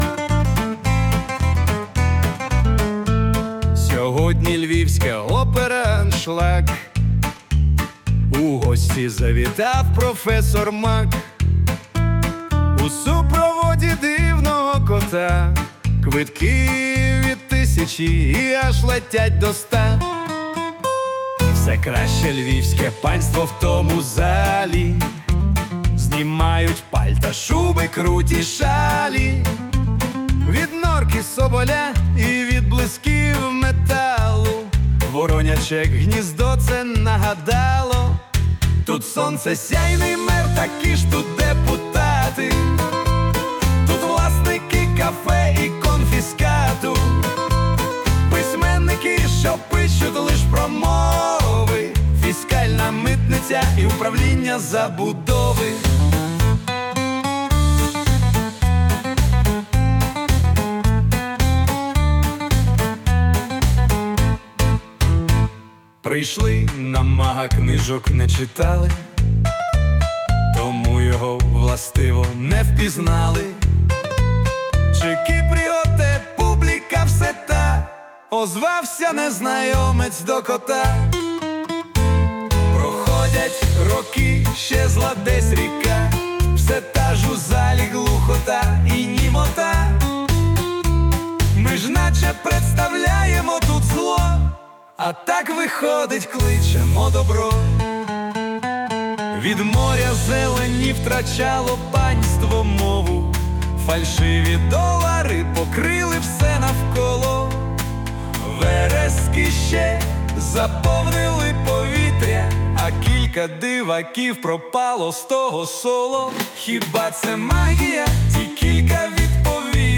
Варіант пісні
музичне прочитання з допомогою ШІ